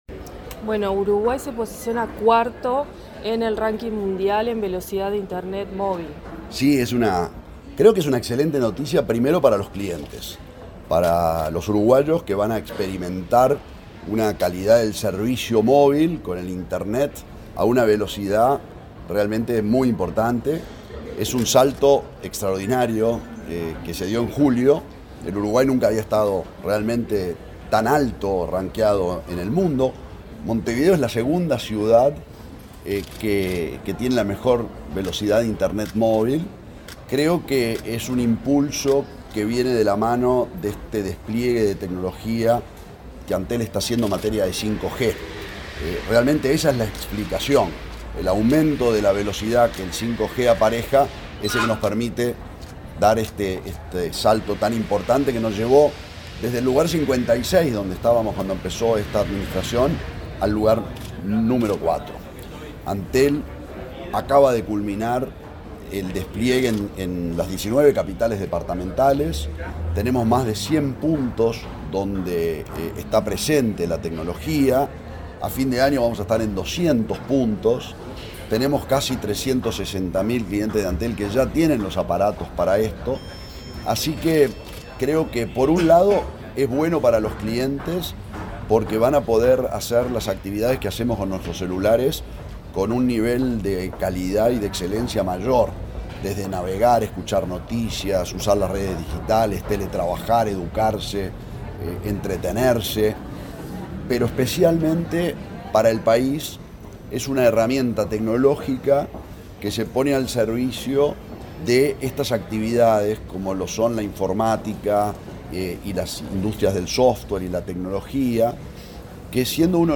Entrevista al presidente de Antel, Gabriel Gurméndez